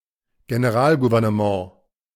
The General Government (German: Generalgouvernement, IPA: [ɡenəˈʁaːlɡuvɛʁnəˌmã]
De-Generalgouvernement.ogg.mp3